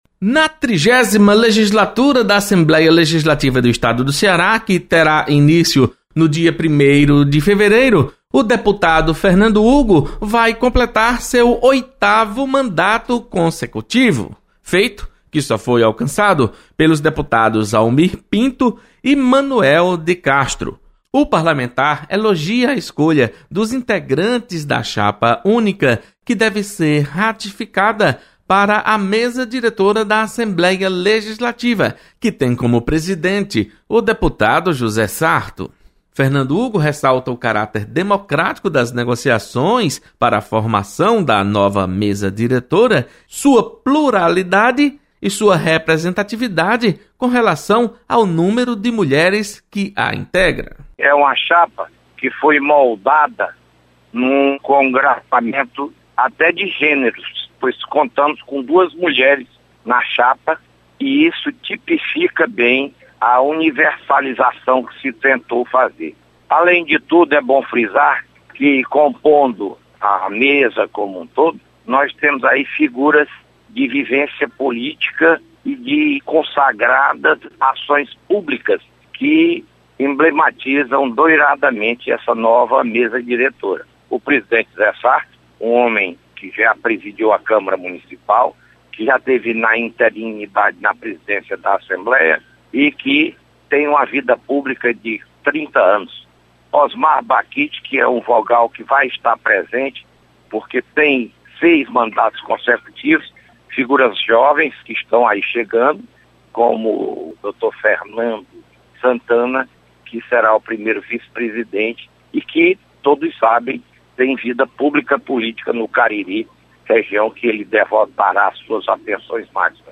Deputado Fernando Hugo destaca formação chapa para concorrer à Mesa Diretora da Assembleia Legislativa. Repórter